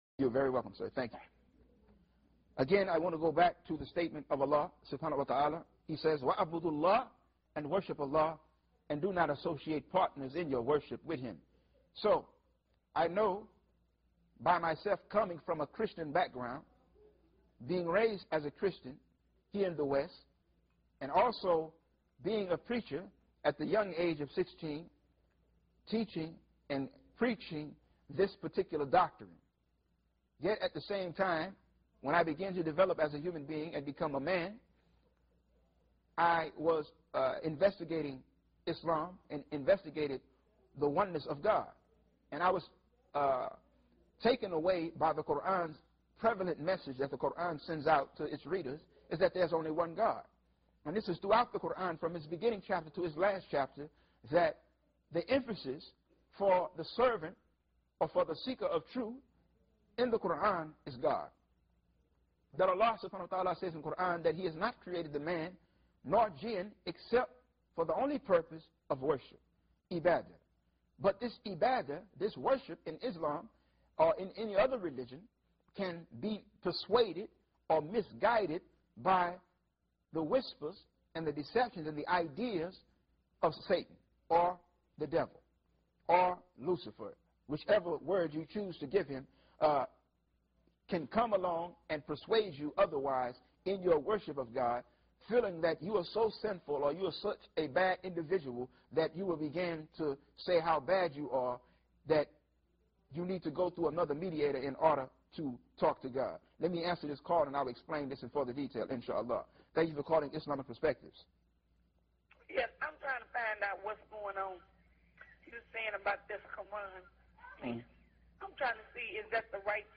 American Woman Becomes Muslim Live on TV